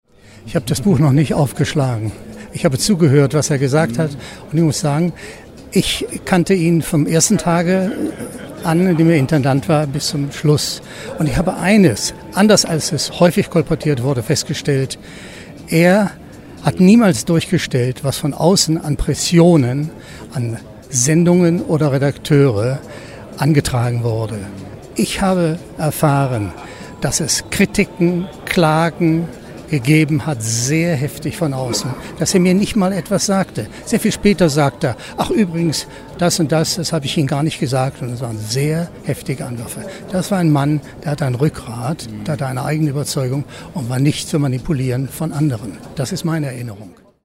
Was: Statement
Wo: Berlin, ZDF-Hauptstadtstudio